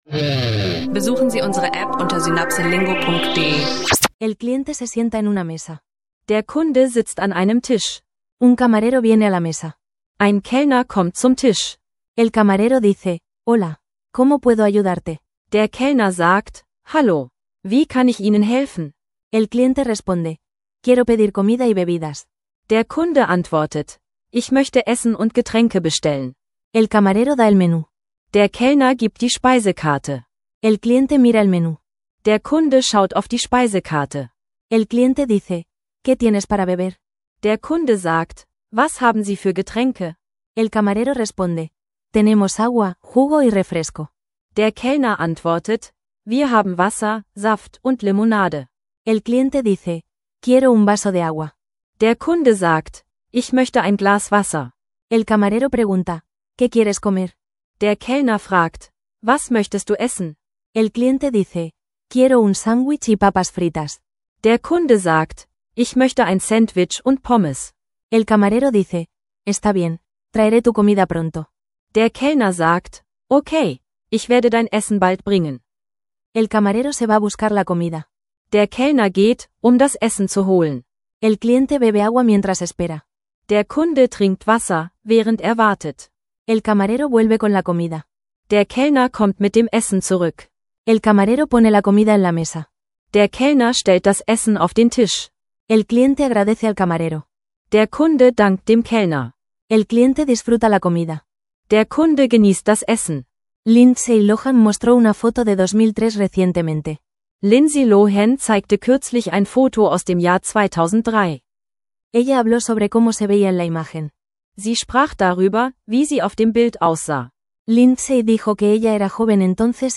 In dieser Folge üben wir typische Dialoge zum Bestellen von Essen und Getränken in einem Restaurant auf Spanisch – ideal für Spanisch lernen, Spanisch lernen online und Spanisch für Anfänger. Mit praktischen Vokabeln und Redewendungen für den Alltag.